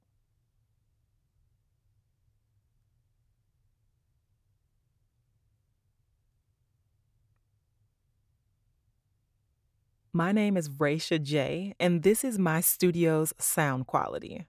Female
Approachable, Authoritative, Bright, Confident, Conversational, Cool, Corporate, Energetic, Engaging, Friendly, Natural, Reassuring, Sarcastic, Upbeat, Versatile, Warm, Young
Southern, Midwest (native)
My voice has been described as warm, sultry, wise, grounded, unpretentious, authoritative, and emotionally engaging, perfect for projects that require a balance of professionalism and relatability.
Commercial.mp3
Audio equipment: Audio Sigma interface and Focusrite Scarlett Solo backup in a sound-treated, carpeted home studio with acoustic treatment for clean, broadcast-quality audio.